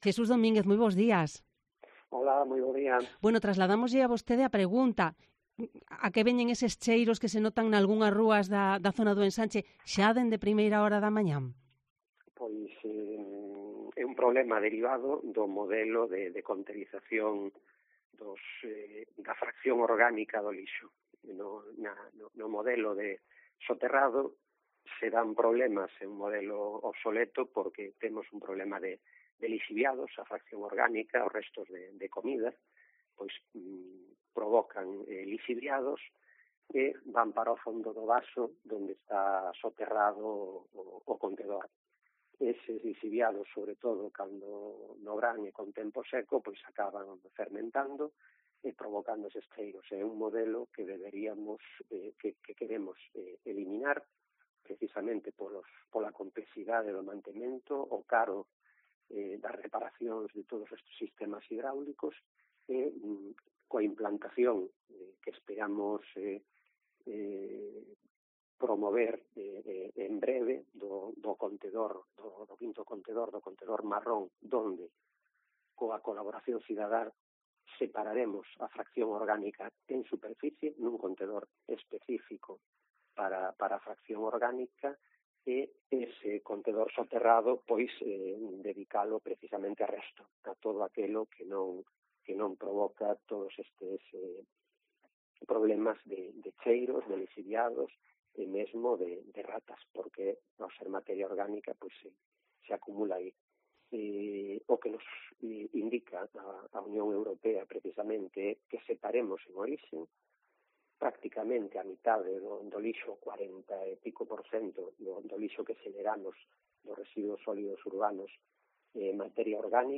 AUDIO: El edil de Limpeza responde en Cope Santiago a varias preguntas que nos ha trasladado la audiencia: ¿sabes por qué huele tan mal desde...